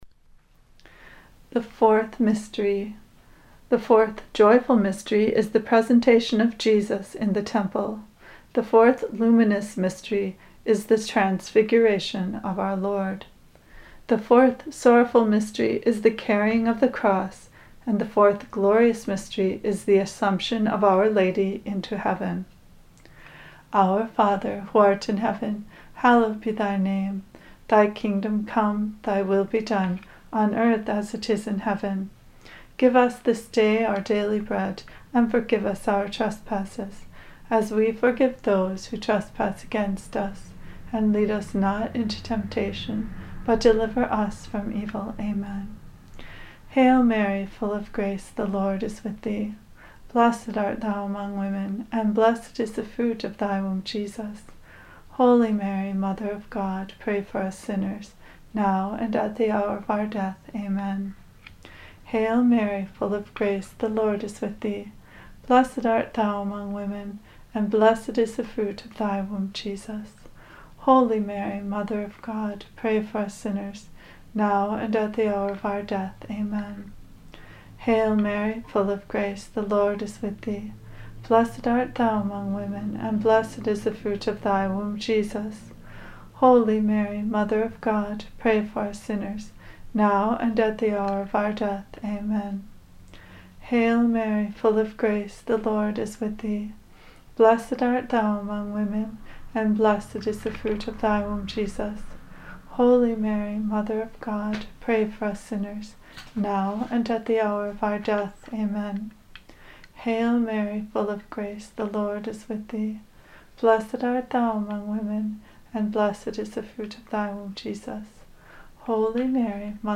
Below you will find some information and the recitation of the Rosary.